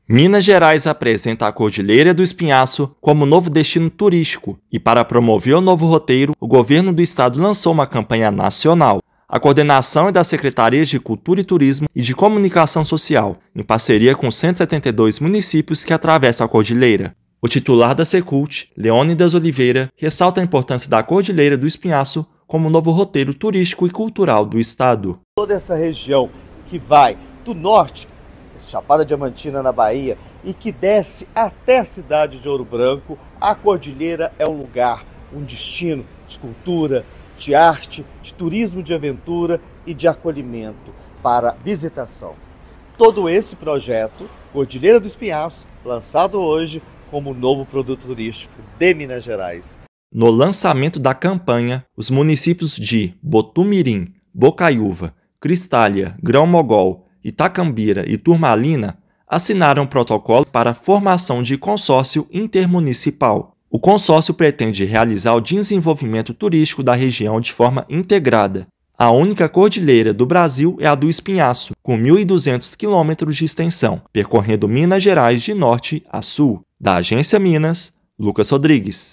Única cordilheira do Brasil abriga um dos maiores patrimônios naturais e culturais do continente. Ouça matéria de rádio.